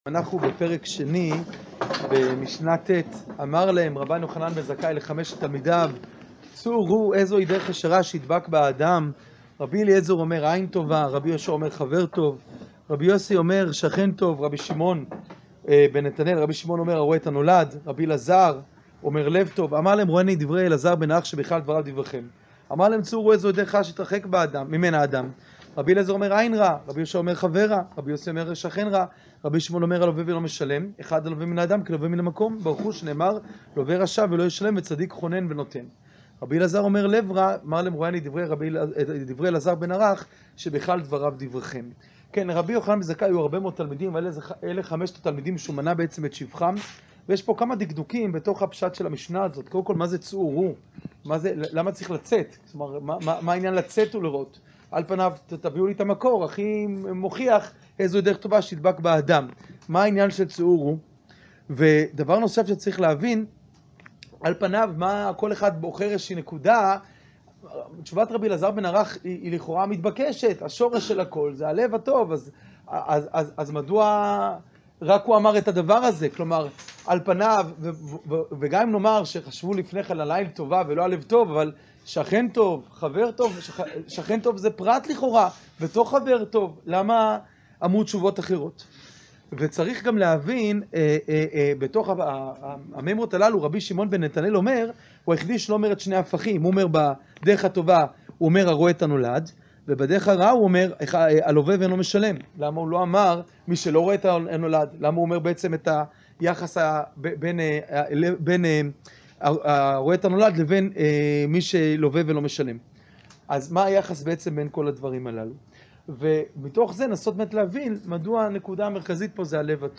שיעור פרק ב משנה ט